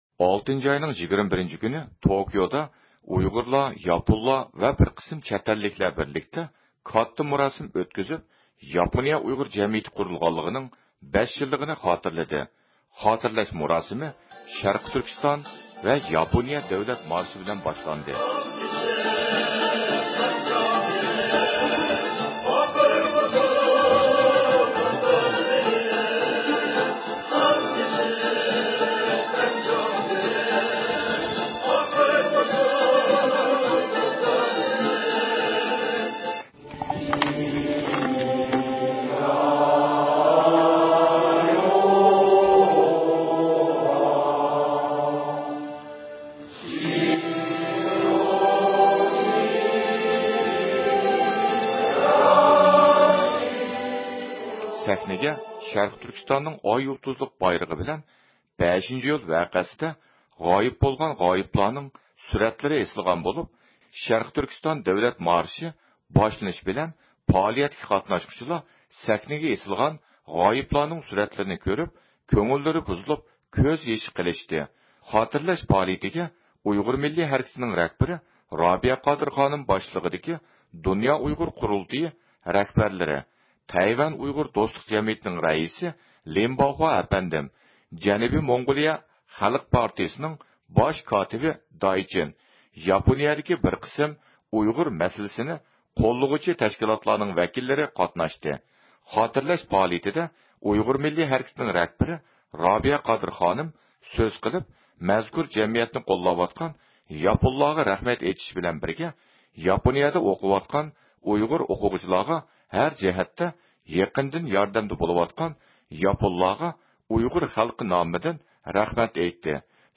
زىيارىتىمىزنى قوبۇل قىلغان رابىيە قادىر خانىم بۇ ھەقتە توختىلىپ ئۆتتى.